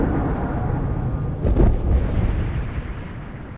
Alarm2_9.ogg